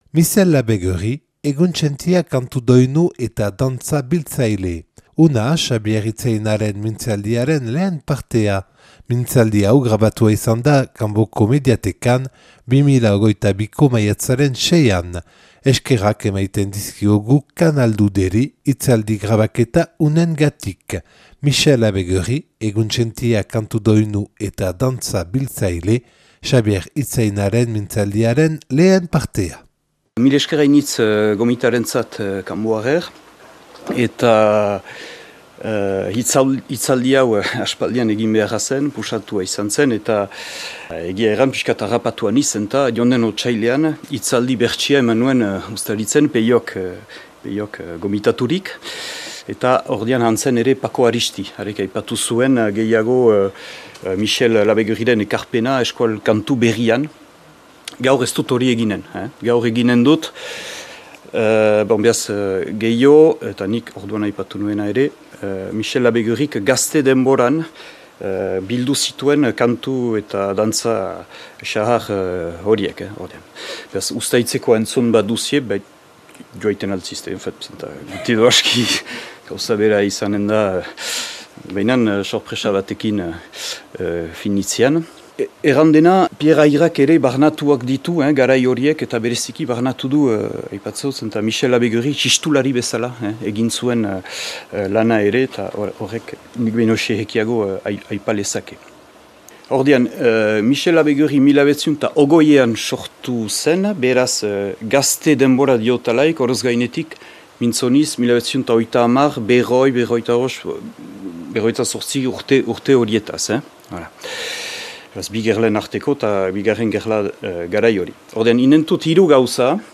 (Mintzaldi hau grabatua izan da Kanboko mediatekan 2022. Maiatzaren 6an).